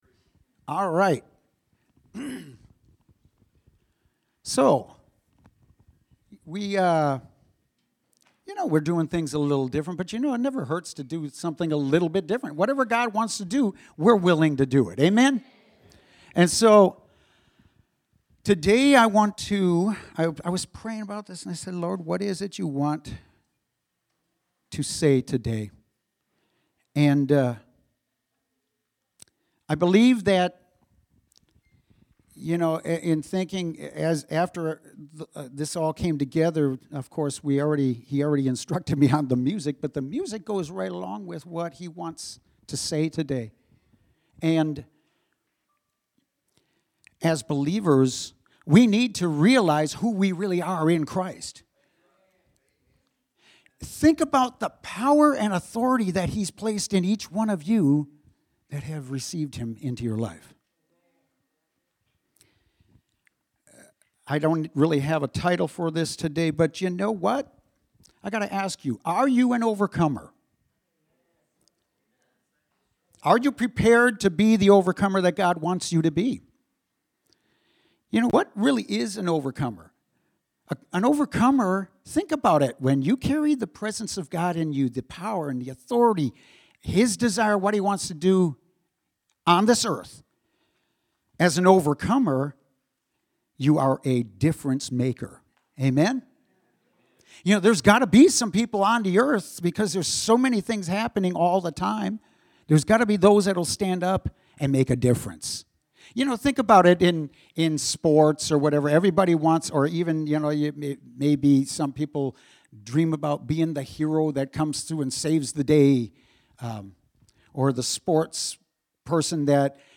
Sermon from Sunday, January 31st, 2021.